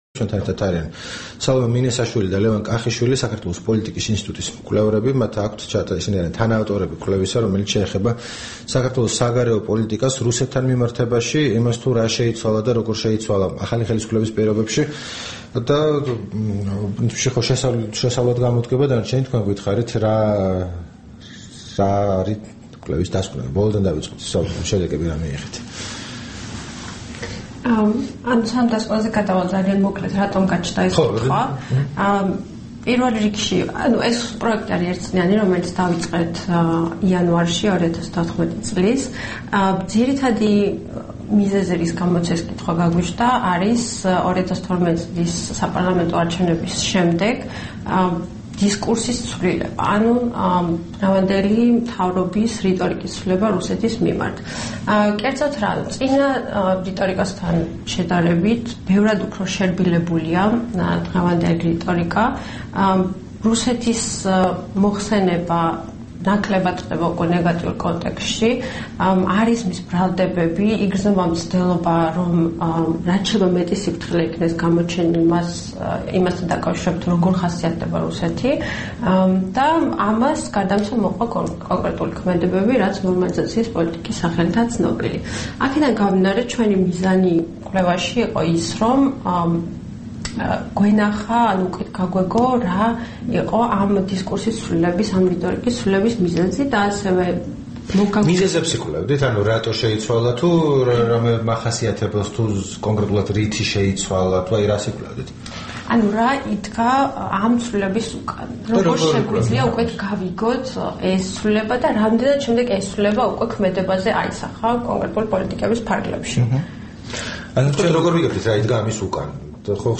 რადიო თავისუფლების თბილისის სტუდიაში სტუმრად იყვნენ
საუბარი